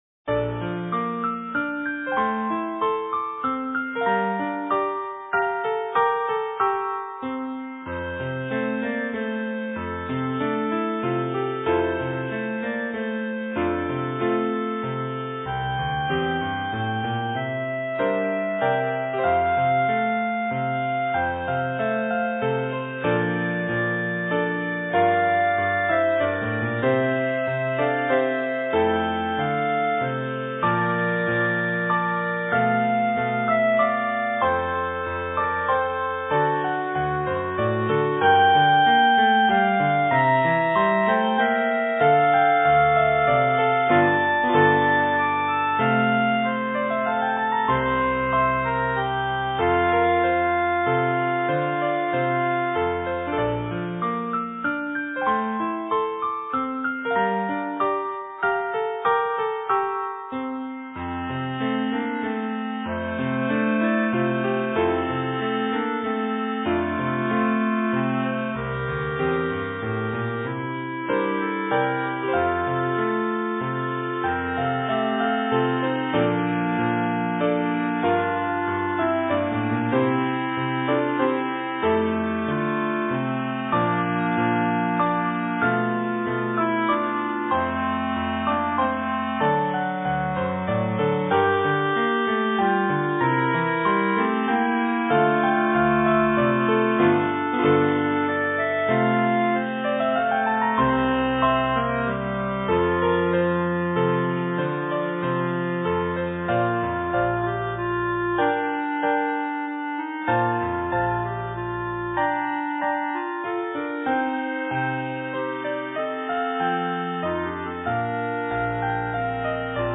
Voicing: Clarinet Duet